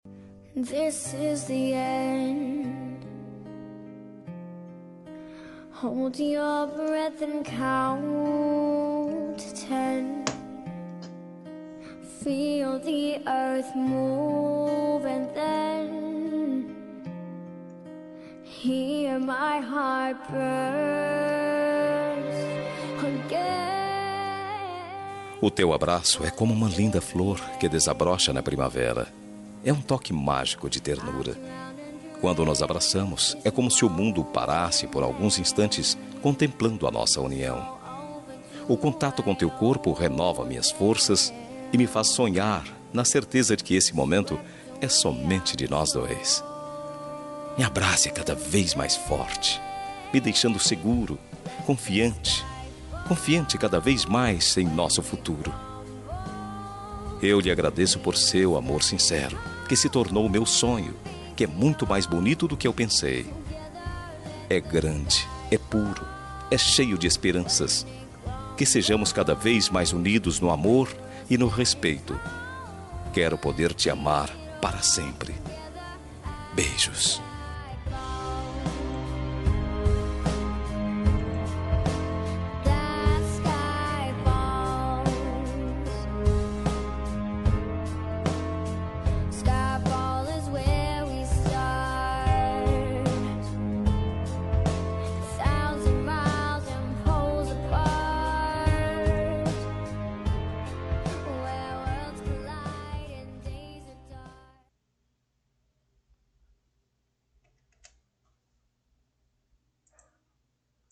Romântica para Esposa – Voz Masculina – Cód: 6723